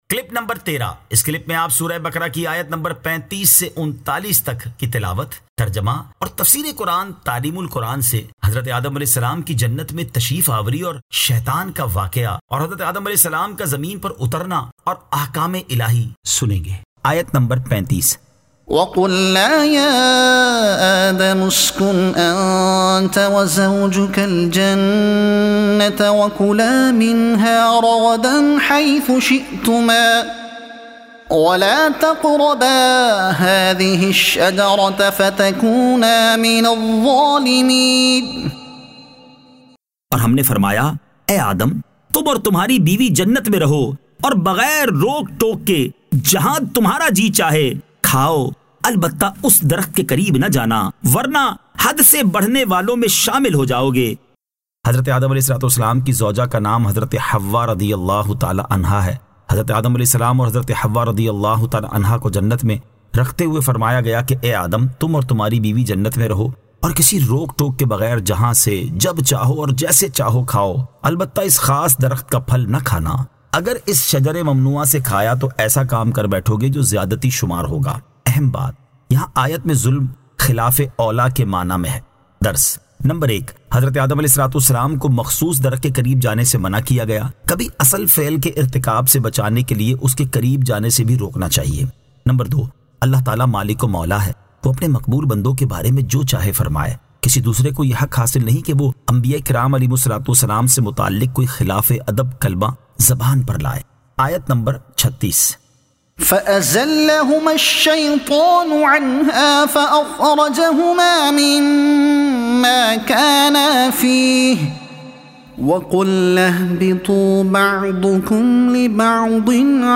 Surah Al-Baqara Ayat 35 To 39 Tilawat , Tarjuma , Tafseer e Taleem ul Quran